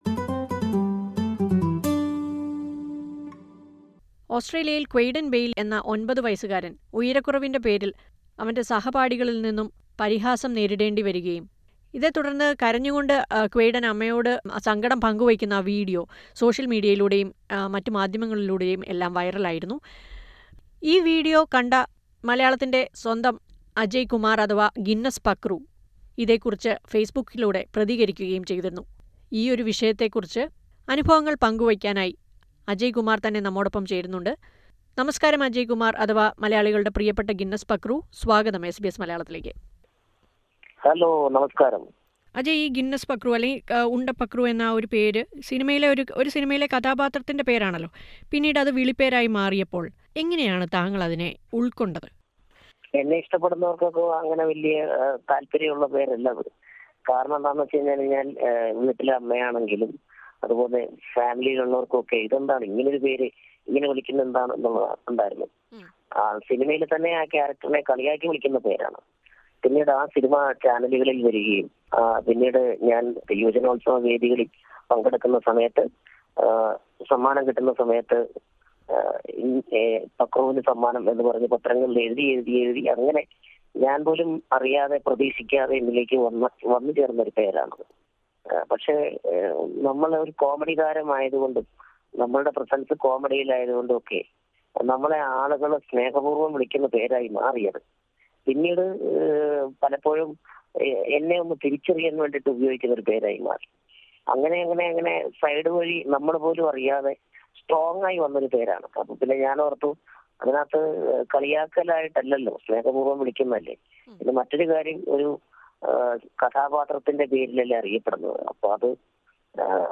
അടുത്ത സുഹൃത്തുക്കളുടെ പോലും പരിഹാസം നേരിടേണ്ടി വന്നതിനെക്കുറിച്ചും, ഉയരക്കുറവ്ന്റെ പേരിൽ സ്കൂളിൽ പ്രവേശനം നിഷേധിച്ചതിന്റെ അനുഭവങ്ങളും അജയ് കുമാർ അഥവാ ഗിന്നസ് പക്രു എസ് ബി എസ് മലയാളത്തോട് പങ്കുവയ്ക്കുന്നത് കേൾക്കാം...